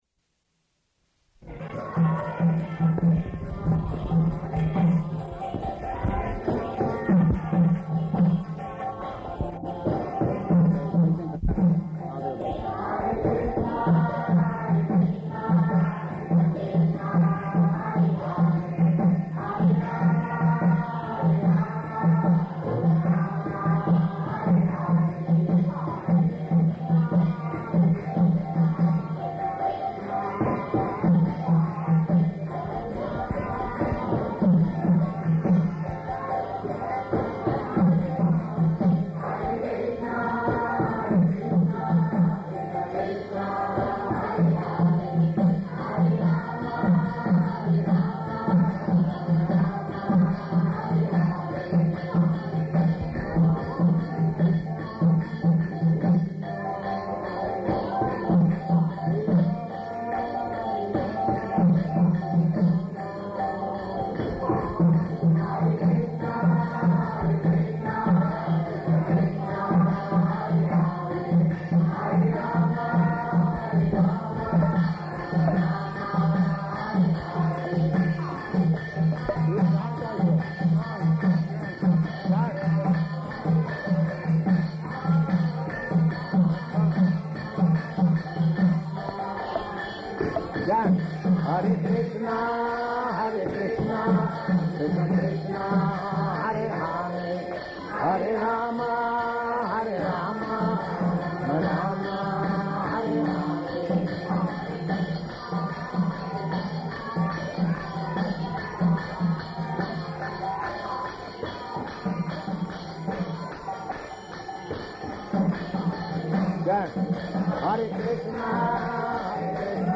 Lecture at Wayside Chapel --:-- --:-- Type: Lectures and Addresses Dated: May 13th 1971 Location: Sydney Audio file: 710513LE-SYDNEY.mp3 [ kīrtana— Prabhupāda urging the audience, "Chant! Chant!" "Ask them to chant."]